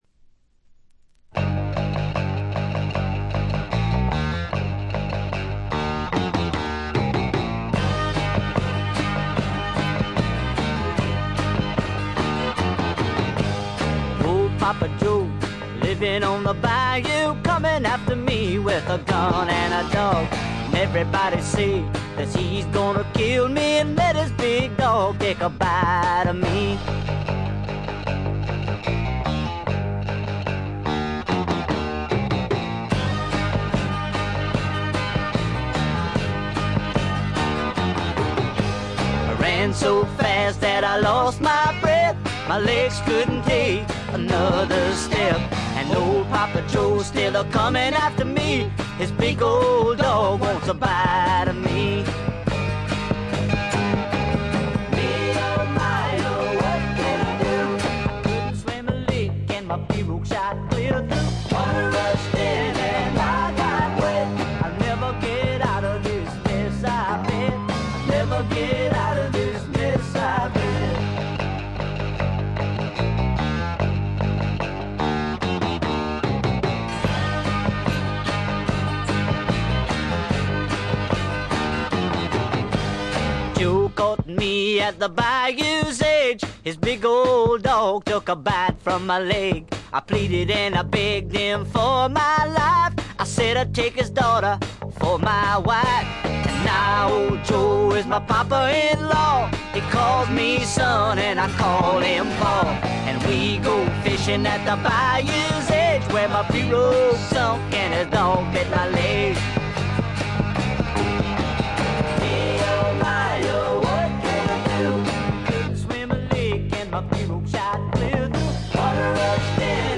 ホーム > レコード：カントリーロック
これ以外は軽微なバックグラウンドノイズ少々、軽微なチリプチ少々。
試聴曲は現品からの取り込み音源です。